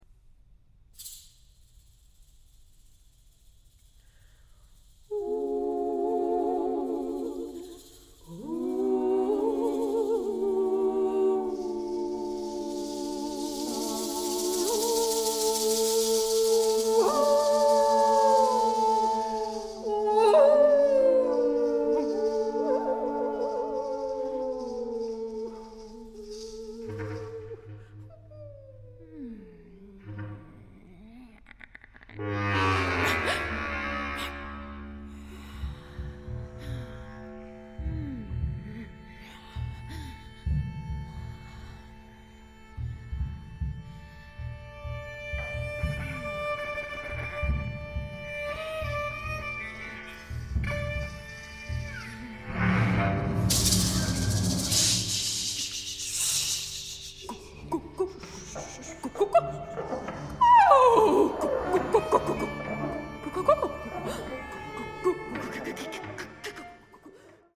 • Genres: Classical, Vocal